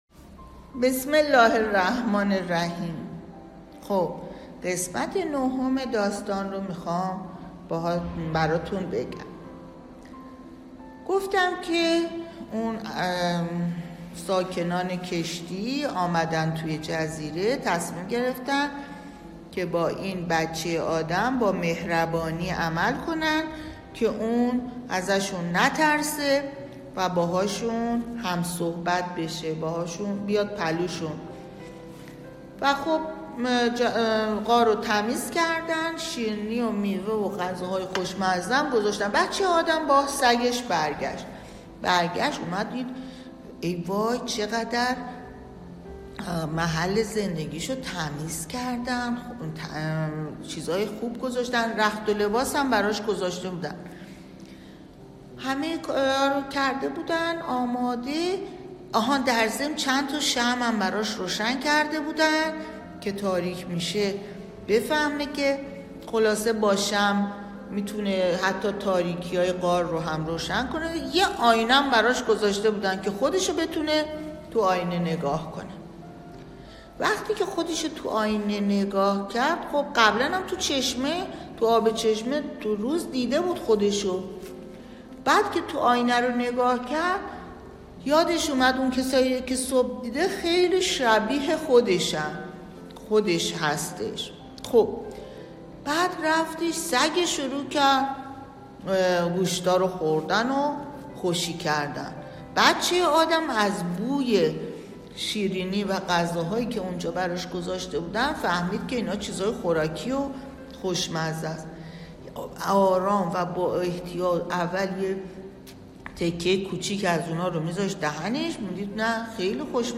از شما دعوت می‌کنیم تا در تعطیلات نوروزی از کتاب های صوتی کتابخانه استفاده کنید.